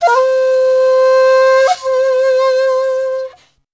FAMOUS FLUTE.wav